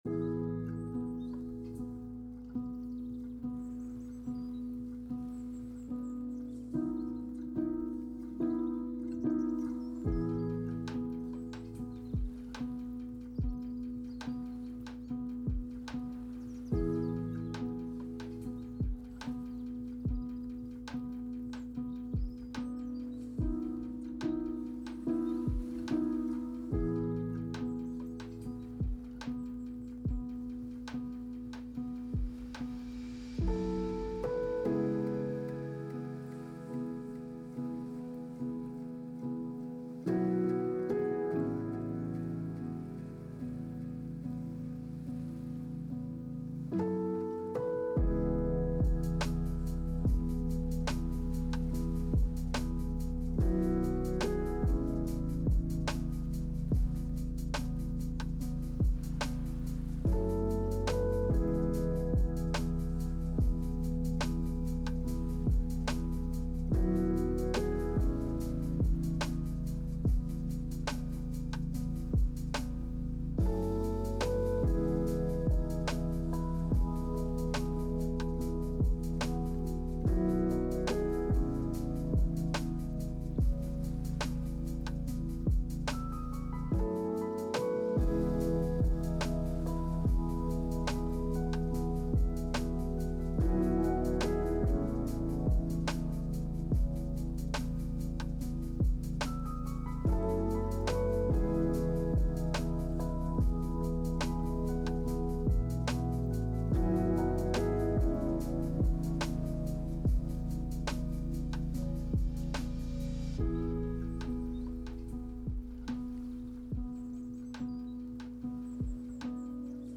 music for practice